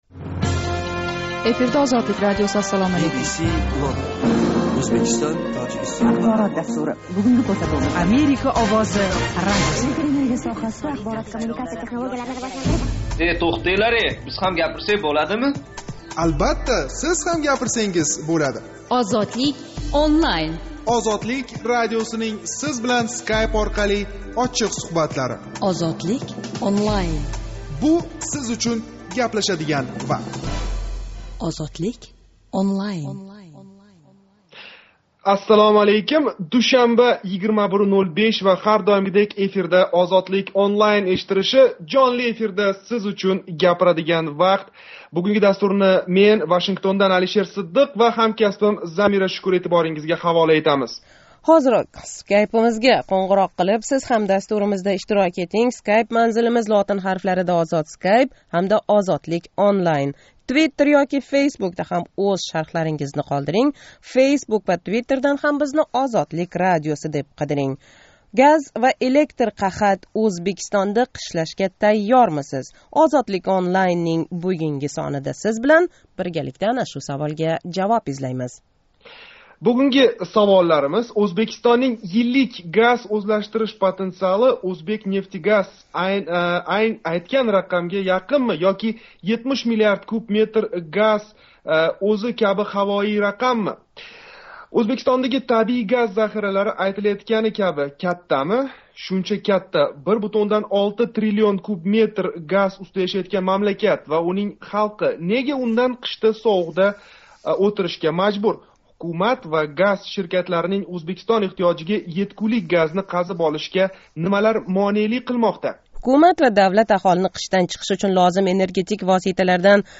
Душанба¸ 29 октябр куни Тошкент вақти билан 21:05 да бошланган OzodlikOnline жонли¸ интерактив мулоқотида Ўзбекистонда кучайиб бораëтган энергетик инқироз сабаб ва оқибатларини муҳокама қилдик.